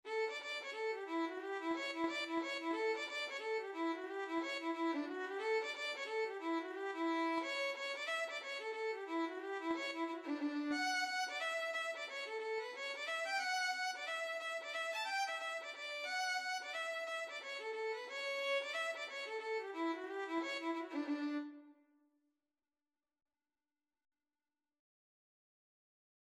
D major (Sounding Pitch) (View more D major Music for Violin )
4/4 (View more 4/4 Music)
Instrument:
Violin  (View more Intermediate Violin Music)
Traditional (View more Traditional Violin Music)
Reels
Irish
picking_cockles_ON1347_VLN.mp3